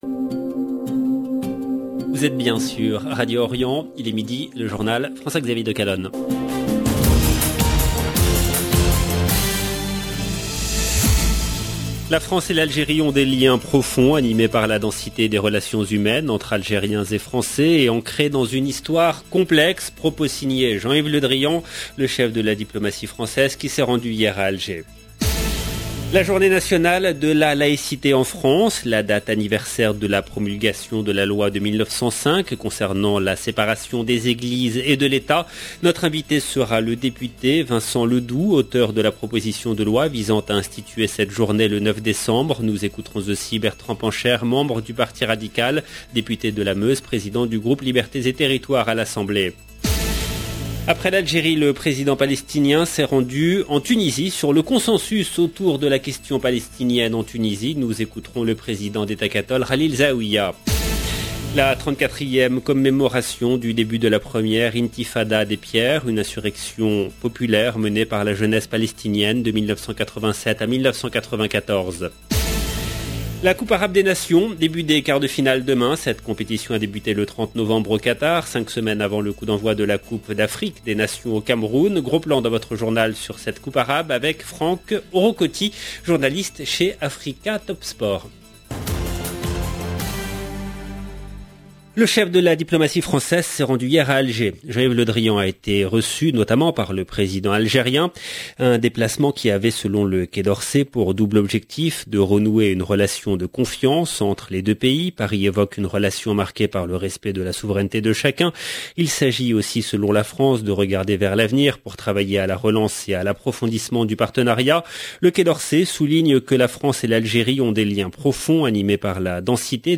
LB JOURNAL EN LANGUE FRANÇAISE
Notre invité sera le député Vincent Ledoux, auteur de la proposition de loi visant à instituer cette journée le 9 décembre. Nous écouterons aussi Bertrand Pancher, membre du parti radical, député de la Meuse, Président du groupe Libertés et Territoires de l’Assemblée nationale.